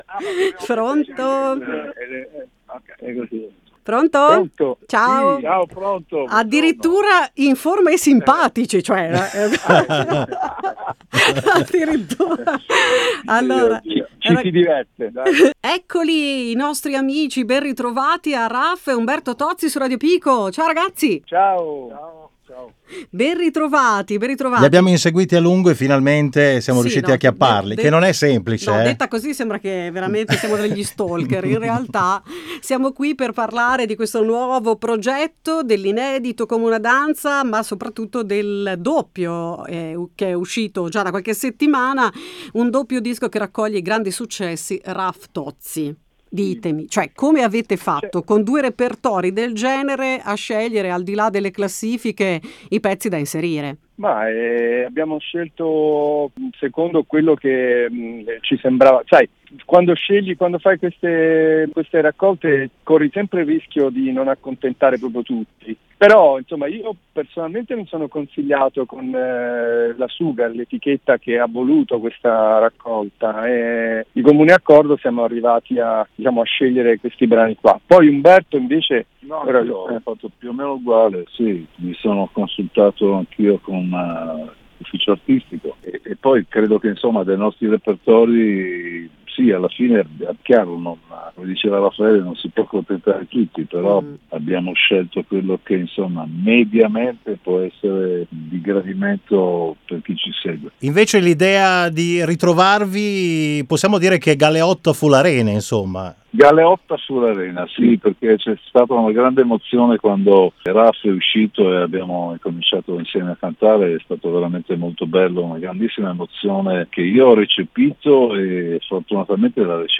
SU RADIO PICO OSPITI TOZZI E RAF